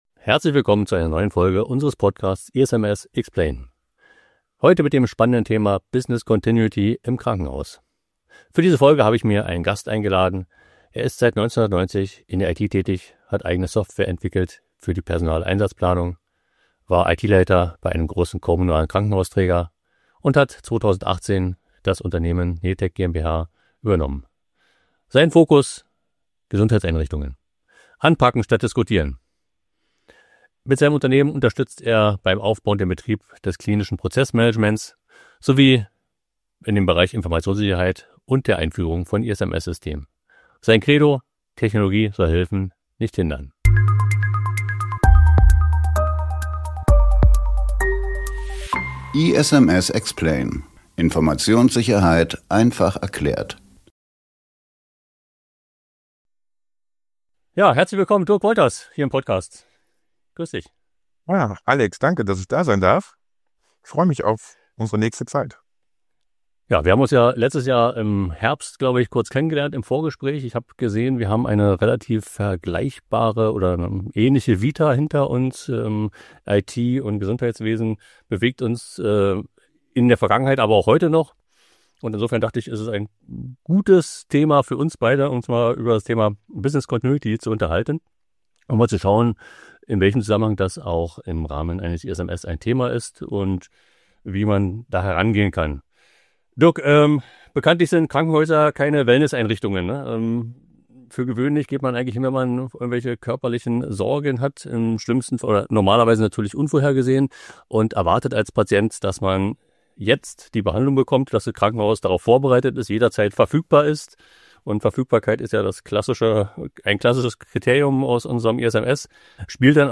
Zwei Experten aus dem Gesundheitssektor tauschen sich aus.